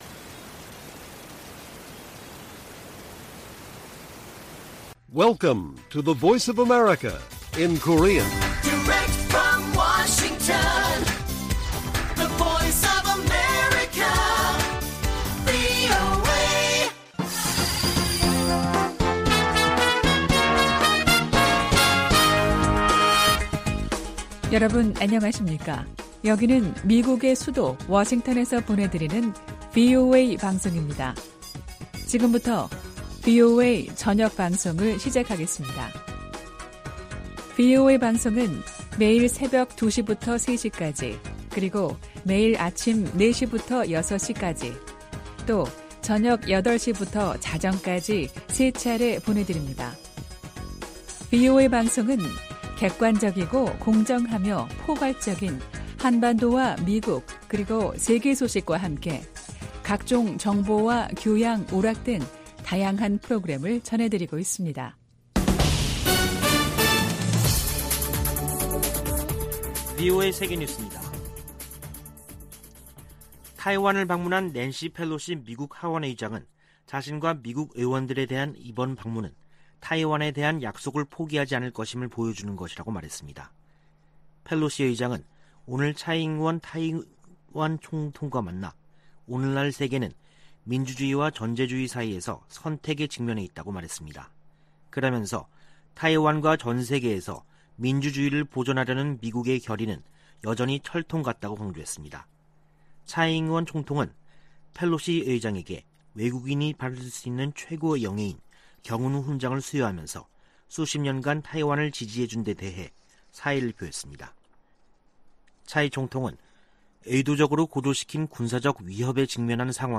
VOA 한국어 간판 뉴스 프로그램 '뉴스 투데이', 2022년 8월 3일 1부 방송입니다. 미 국무부는 ‘확인된 정보’가 없다는 중국 측 주장에 대해, 북한이 7차 핵실험을 준비하고 있는 것으로 평가한다고 밝혔습니다.